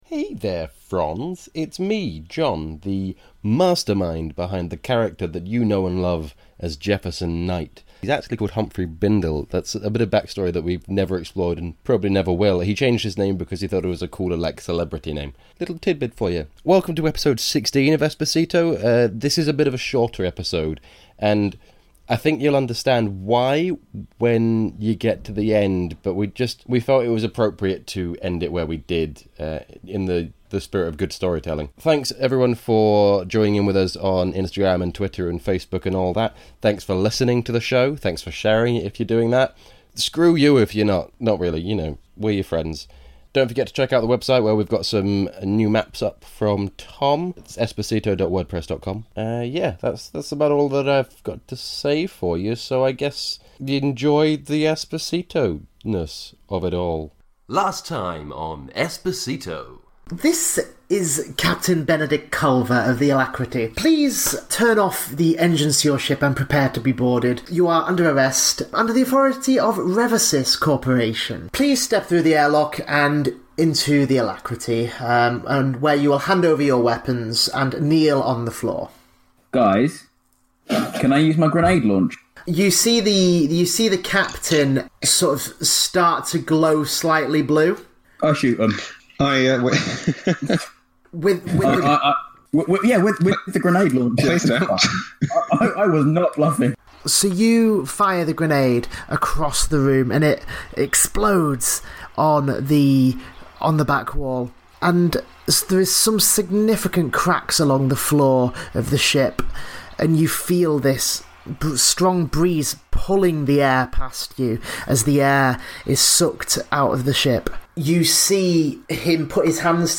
This, the first live action Esper Genesis podcast, tells the ongoing saga of The Epsilon Shift, an innocent group of idiots who have been framed for a terrorist attack.